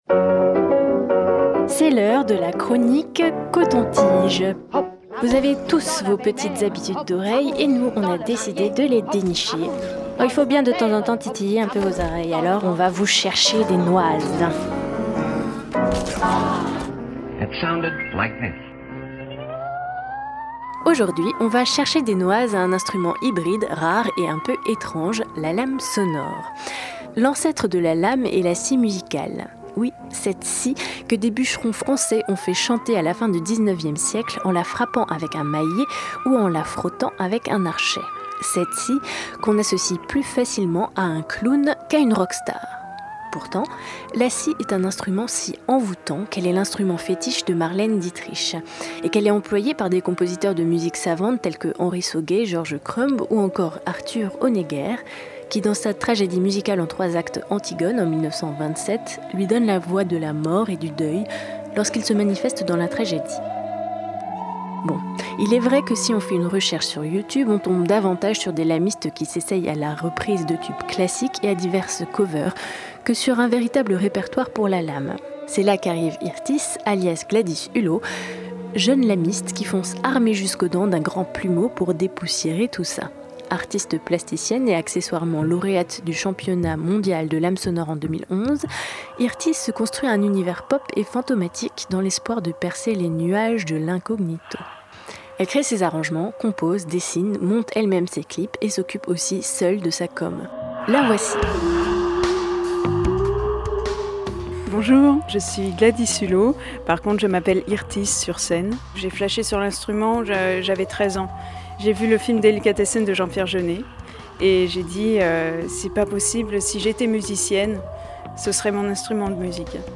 Elle se courbe en forme de "S" puis, frottée avec un archet de violon en un point précis, la lame délivre des mélodies aux sonorités envoûtantes et fantastiques. Capable d'une grande finesse expressive lorsqu'elle est bien maîtrisée, elle couvre jusqu'à quatre octaves.